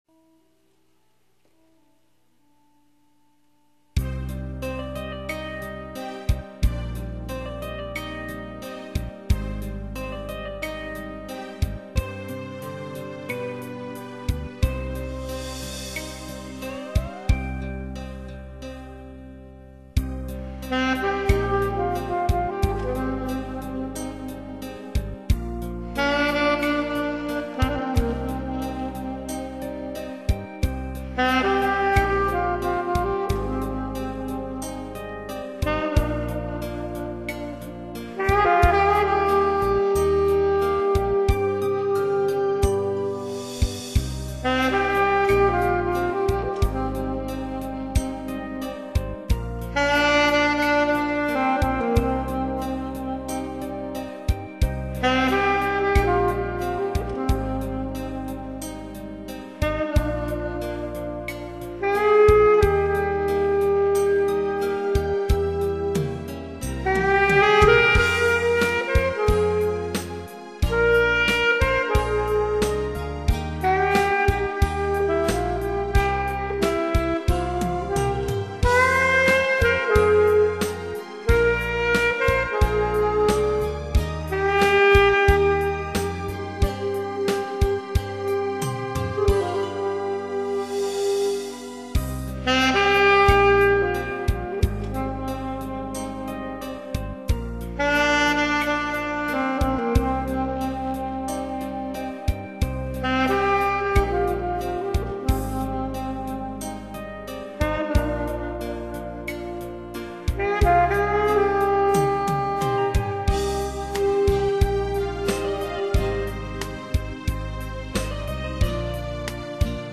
어제 마침 숙직하고 오늘은 일찍 퇴근해서 연습실로 달려가서 녹음했습니다.